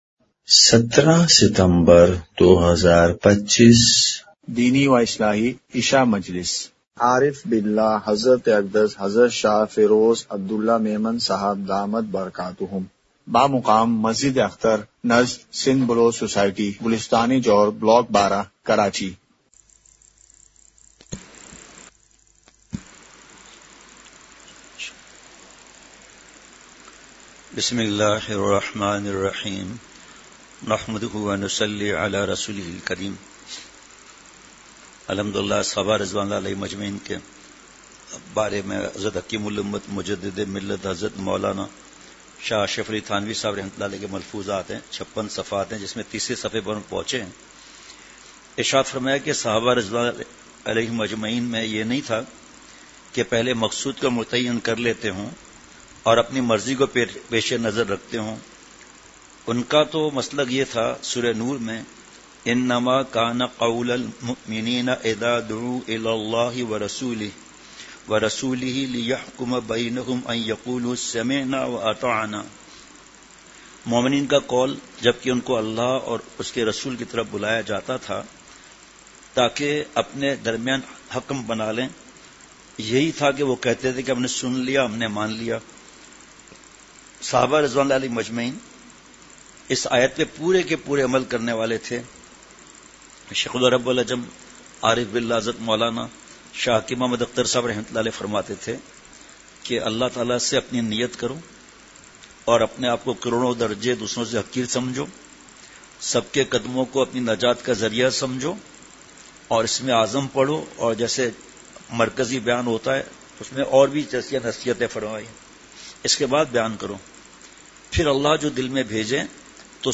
اصلاحی مجلس کی جھلکیاں *مقام:مسجد اختر نزد سندھ بلوچ سوسائٹی گلستانِ جوہر کراچی*